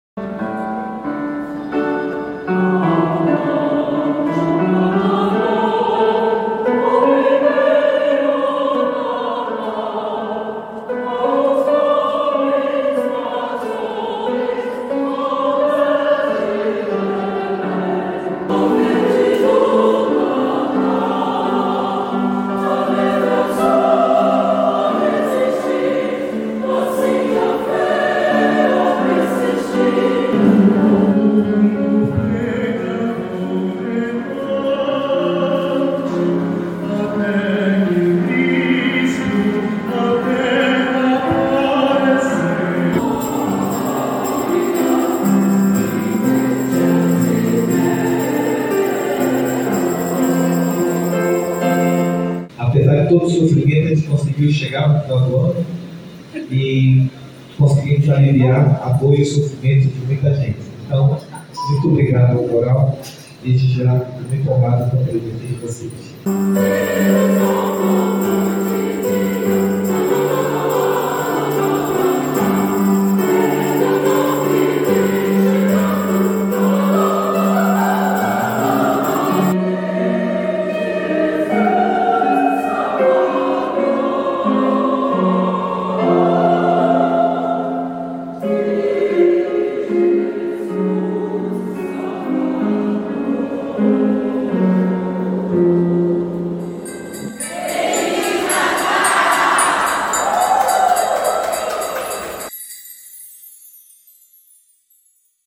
Natal na FUHAM 2024 tem recital do Madrigal da SEC em repertório popular e erudito
Para uma plateia formada por servidores e usuários, o Madrigal do Amazonas, grupo vocal dos Corpos Artísticos da Secretaria de Estado de Cultura e Economia Criativa do Amazonas (SEC/AM), apresentou um recital composto de músicas natalinas, entre populares e clássicas.
que é formado por homens e mulheres entre 18 e 55 anos
arrancou aplausos do público